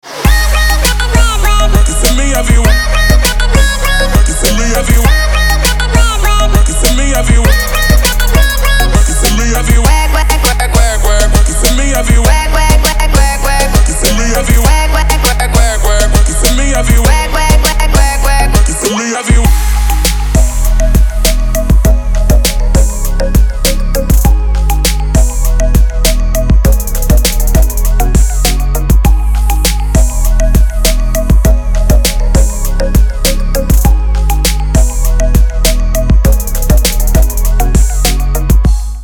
• Качество: 256, Stereo
Trap